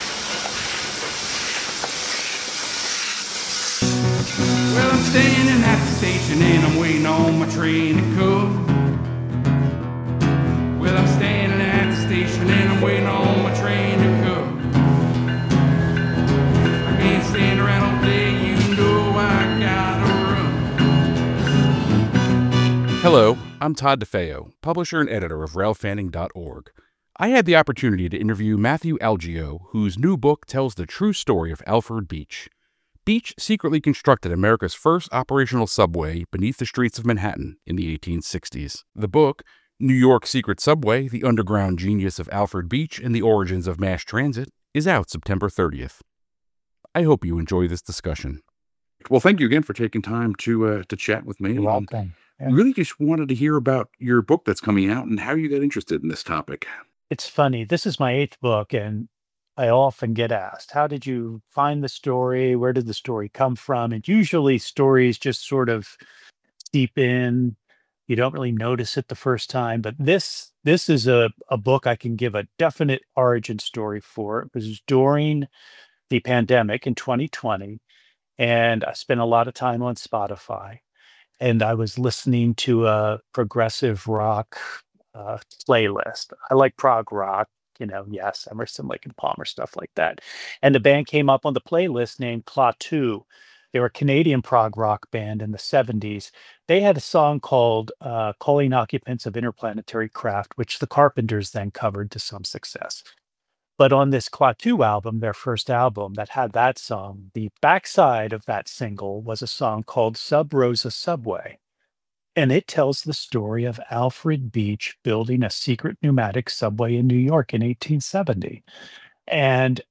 Show Notes This conversation snippet has been slightly edited.